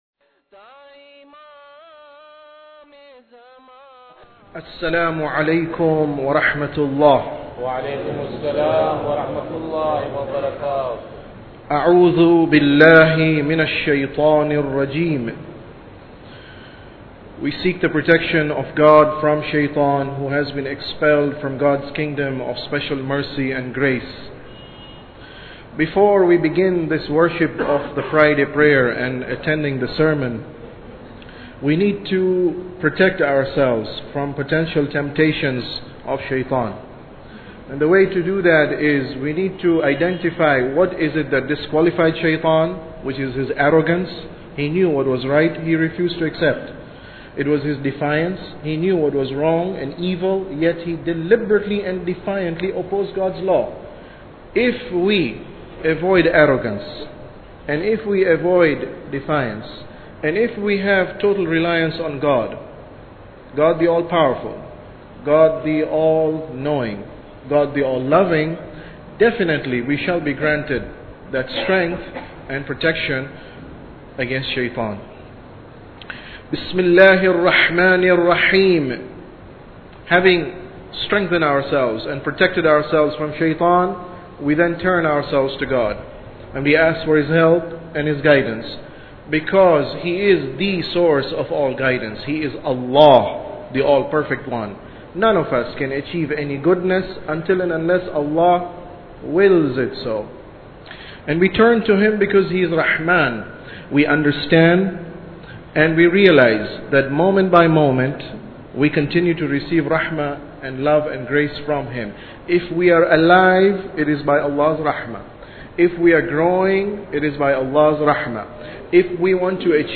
Sermon About Tawheed 12